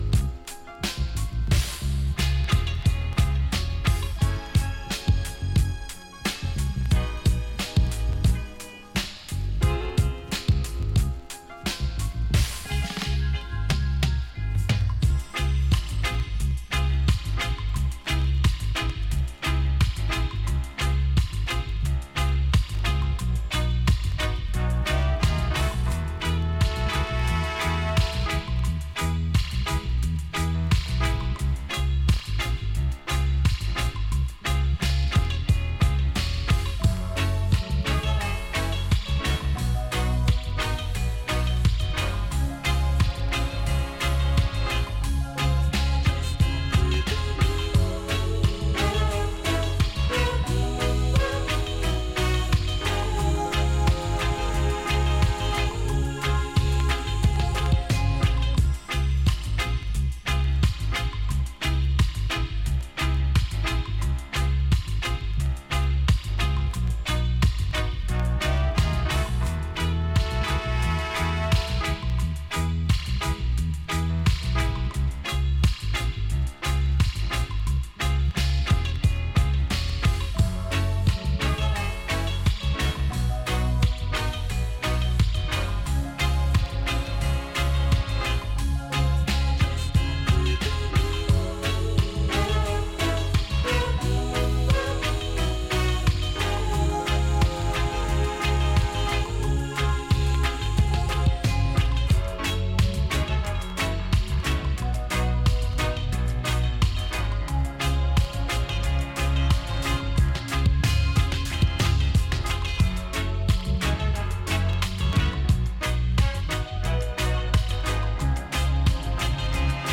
in a Lovers Rock style